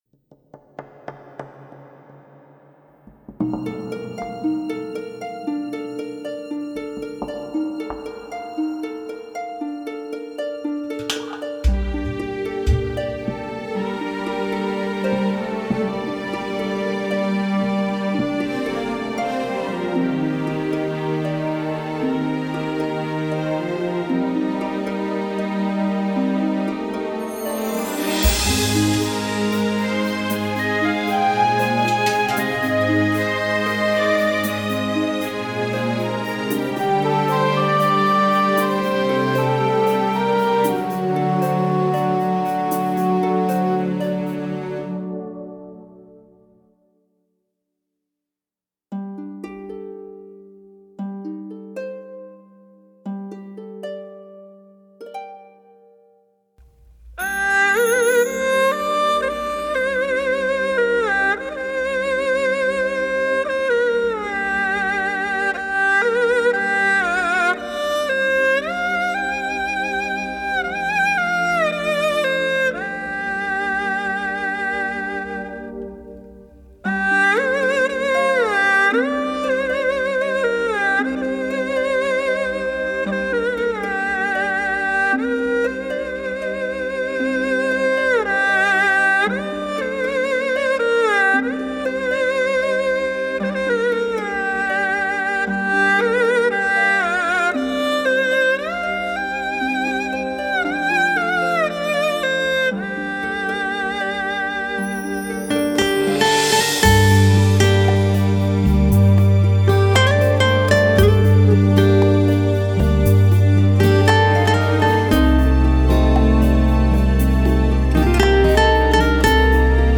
所谓：不鸣则矣，一鸣惊人，二胡的忧怨音色和灵巧的速度感表露无遗，
二胡——人声，两个各自优秀的艺术家，却用音乐搭起了桥梁，
闭上眼睛，用心去听这具有传统风味的二胡，相信你一定会喜欢上它的。